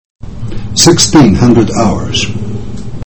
16:00 προφέρεται sixteen hundred hours,